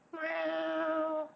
meow1.wav